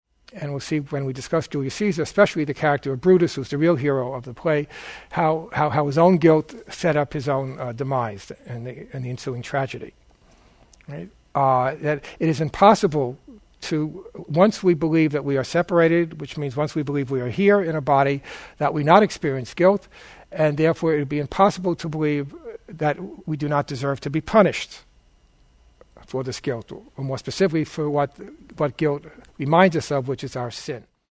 Shakespeare's Julius Caesar is the framework for this workshop, which centers on the consequences of guilt that festers in the unhealed mind.